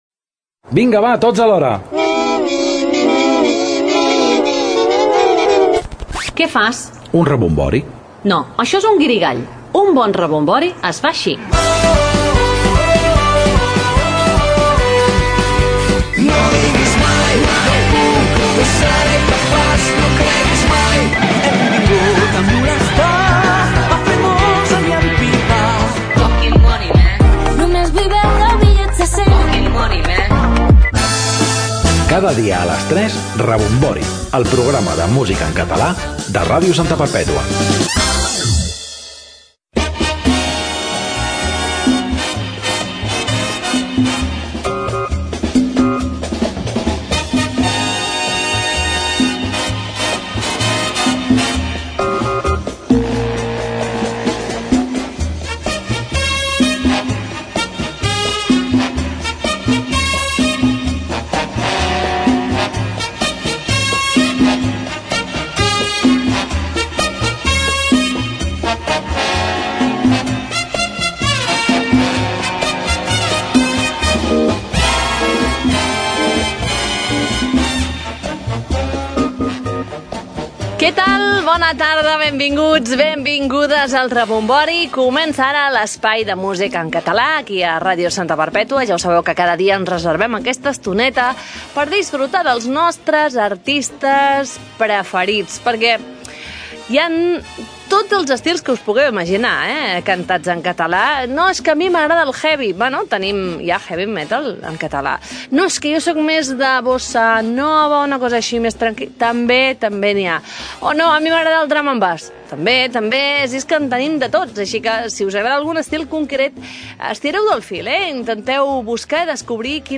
Careta del programa, presentació, grups que es podran escoltar, indicatiu del programa i tema musical
Musical